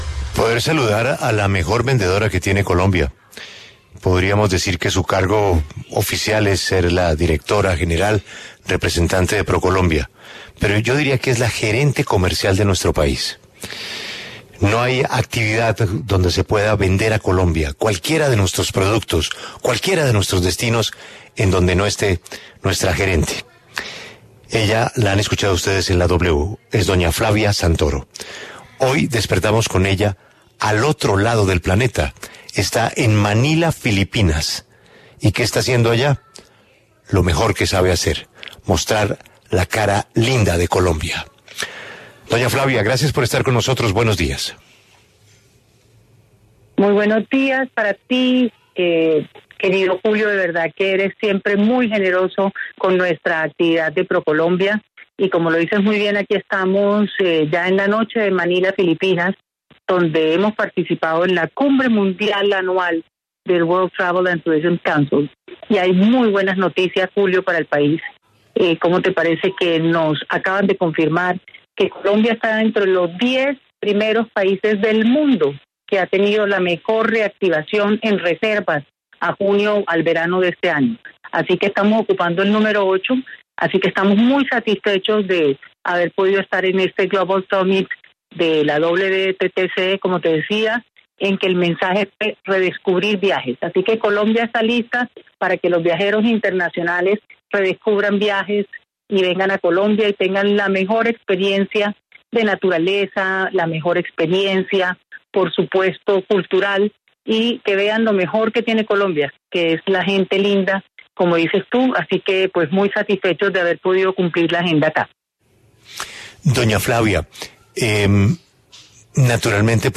Flavia Santoro, presidenta de ProColombia, conversó con La W sobre su agenda en Manila.